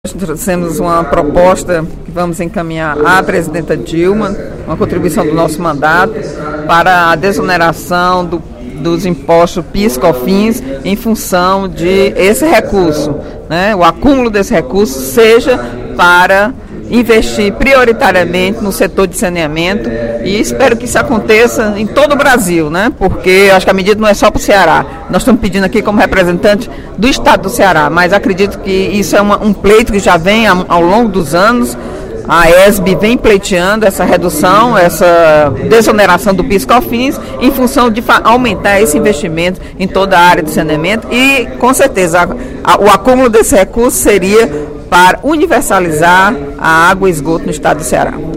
A deputada Eliane Novais (PSB) fez pronunciamento nesta quarta-feira (17/04), durante o primeiro expediente, para destacar a aprovação, no Senado Federal, do Estatuto da Juventude.